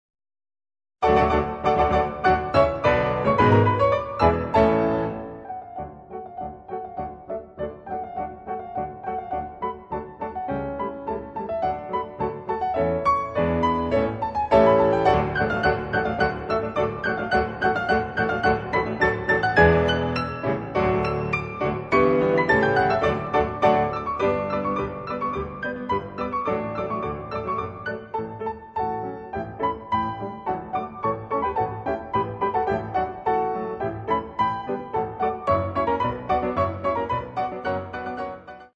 Walzer, Polkas und Märsche
Transkriptionen für Klavier zu vier Händen